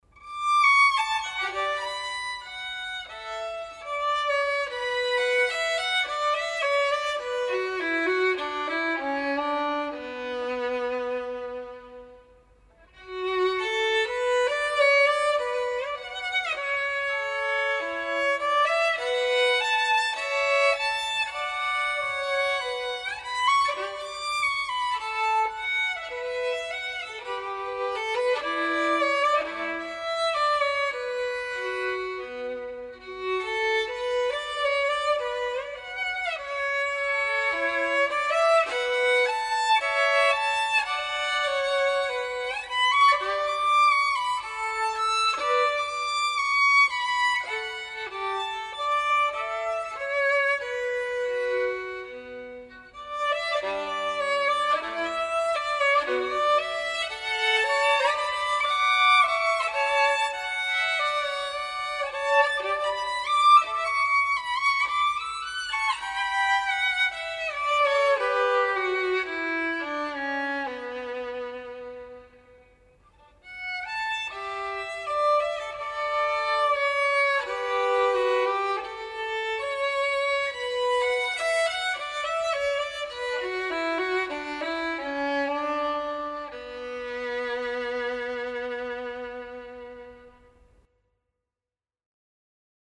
そんなわけで今日は「空中散歩」という曲を作ってみました。 ふわりふわりと（現実はがくりがくりだったが）空中を移動する、 少々戸惑い（現実は絶叫ものだったが）ながら地上に降りてくる様を音にしてみました。